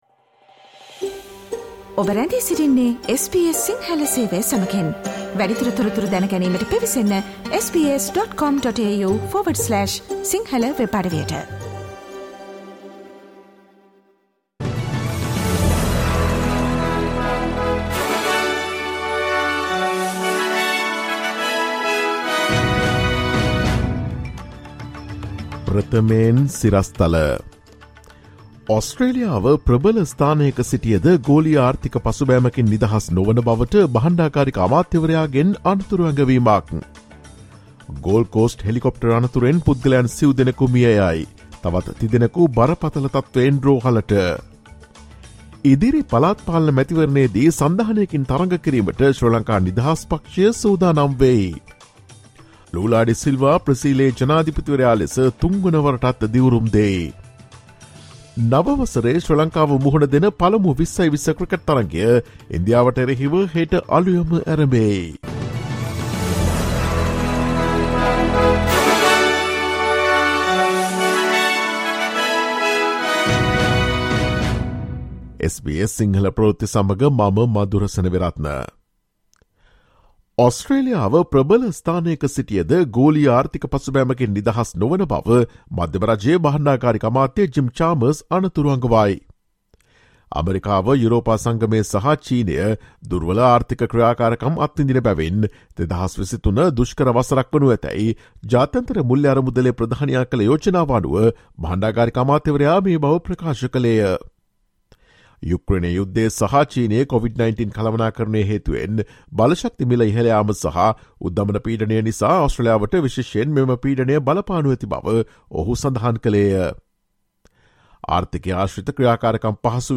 ඔස්ට්‍රේලියාවේ සහ ශ්‍රී ලංකාවේ නවතම පුවත් මෙන්ම විදෙස් පුවත් සහ ක්‍රීඩා පුවත් රැගත් SBS සිංහල සේවයේ 2023 ජනවාරි 03 වන දා අඟහරුවාදා වැඩසටහනේ ප්‍රවෘත්ති ප්‍රකාශයට සවන් දෙන්න.